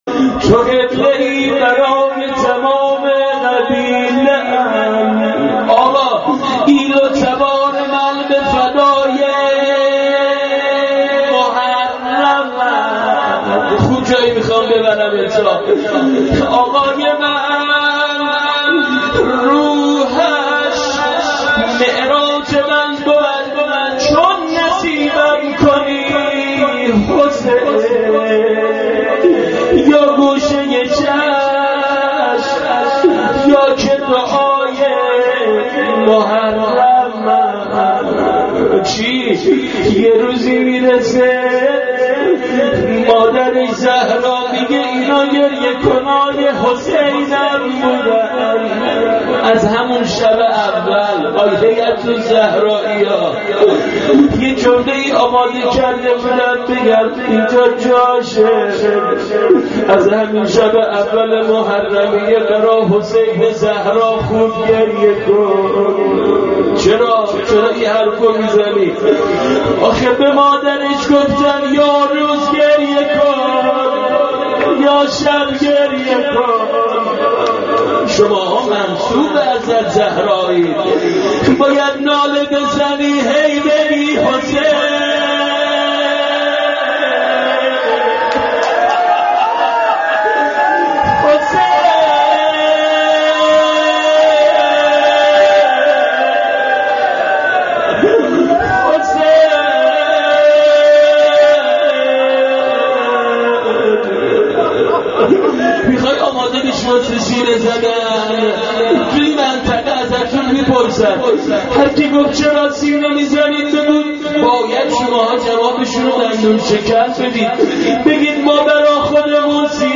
روضه حضرت زهرا-------اذن ورود به محرم.MP3